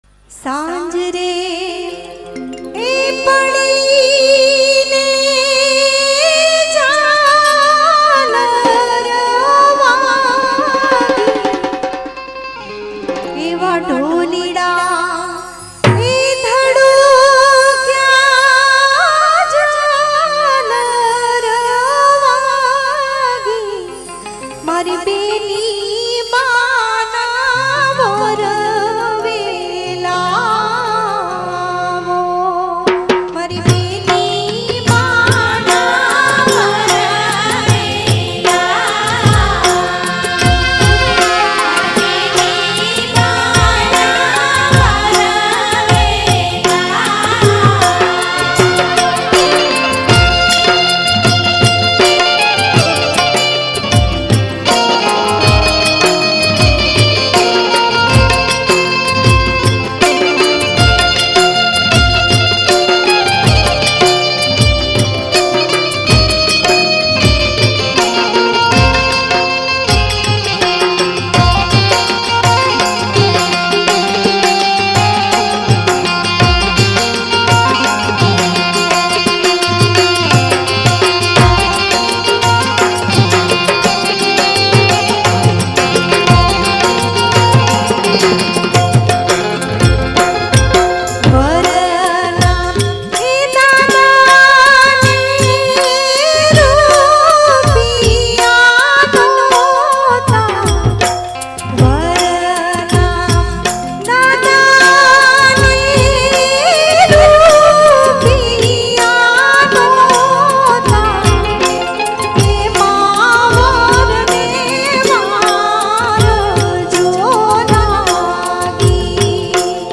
Gujarati Lagan Geet